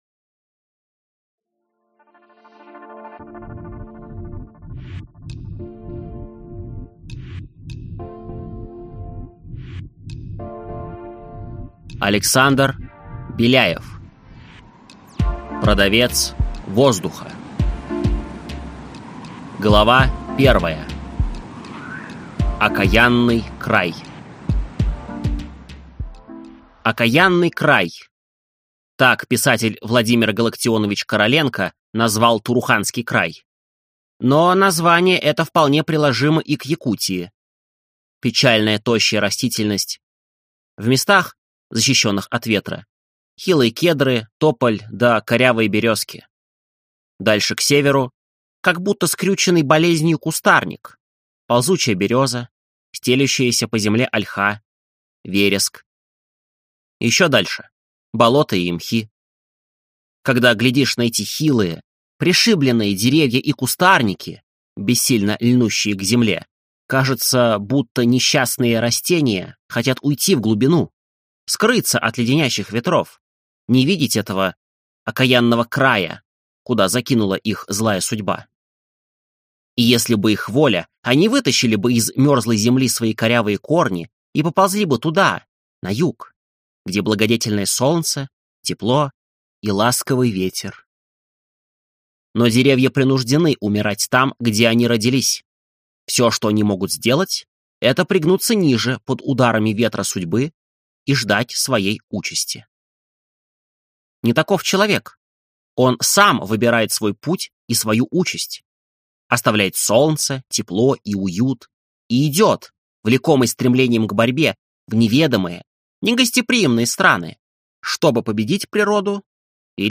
Аудиокнига Продавец воздуха | Библиотека аудиокниг